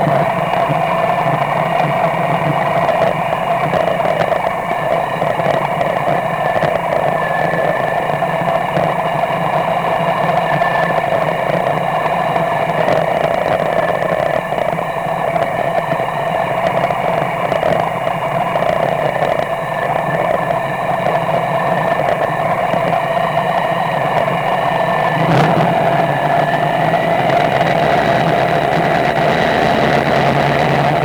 Jeg har samlet nogle lydprøver på hvordan det lyder i stedoskopet når man lytter til en Fiesta motor 1,0 MKI som er i orden.
Generator: Jævn metallisk støj, lejrene må ikke kunne høres.
generatorlyd.wav